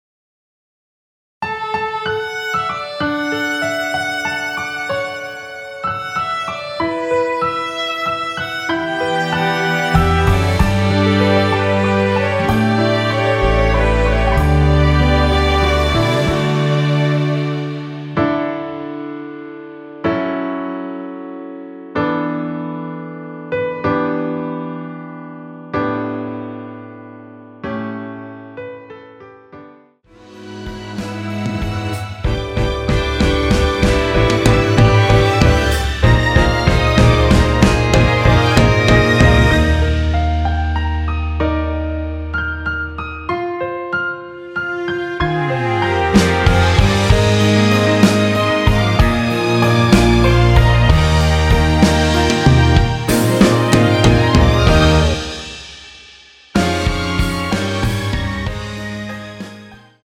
원키에서(+3)올린 MR입니다.
◈ 곡명 옆 (-1)은 반음 내림, (+1)은 반음 올림 입니다.
앞부분30초, 뒷부분30초씩 편집해서 올려 드리고 있습니다.
중간에 음이 끈어지고 다시 나오는 이유는